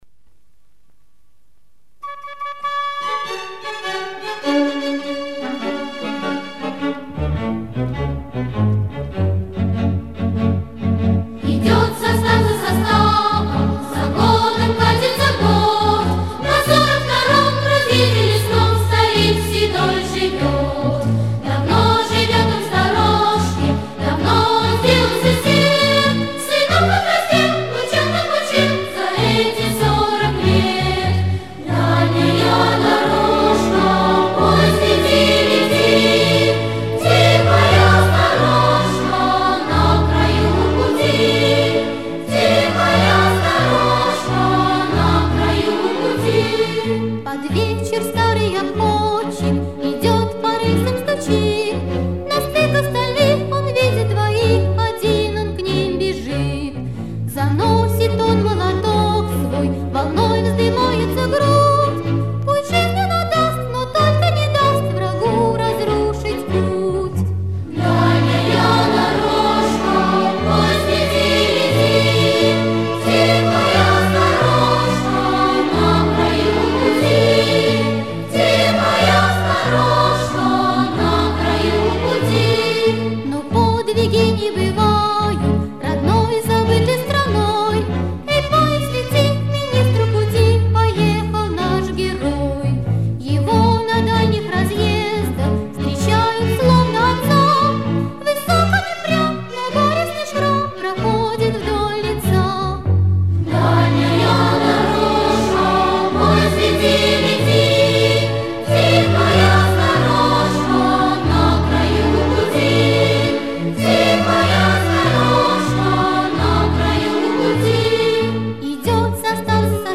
В исполнении детского хора